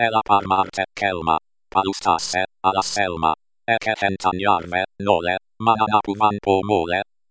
By espeak-ng TTS